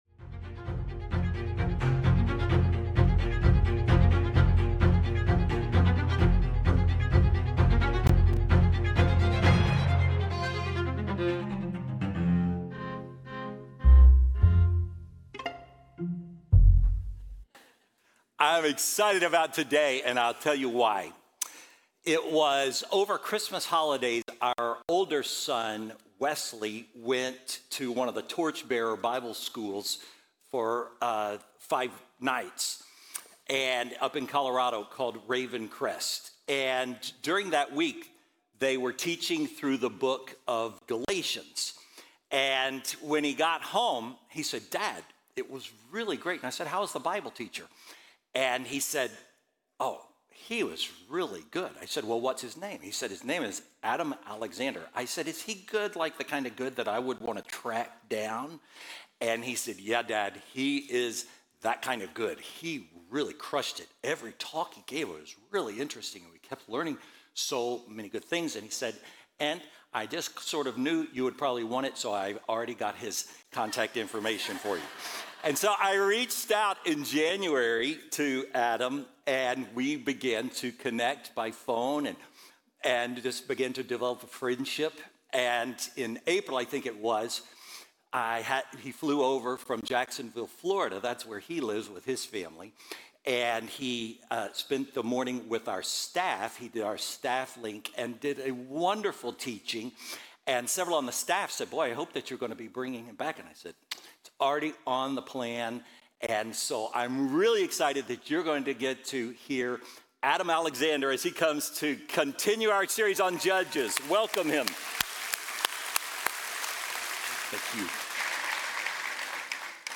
Faithbridge Sermons The Rise of Samson Jul 06 2025 | 00:35:59 1x 00:00 / 00:35:59 Subscribe Share Apple Podcasts Spotify Overcast RSS Feed Share Link Embed